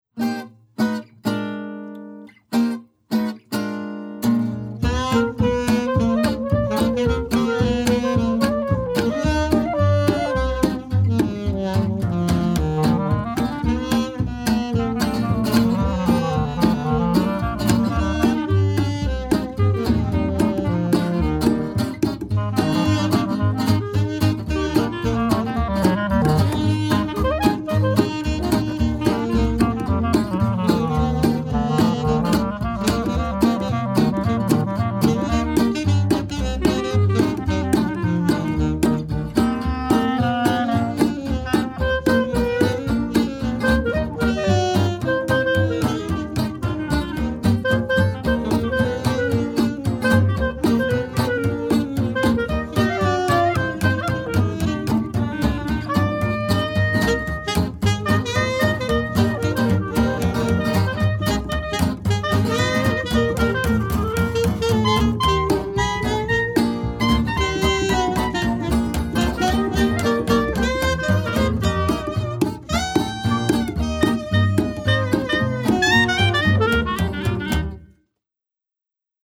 1920s Vintage Jazz Band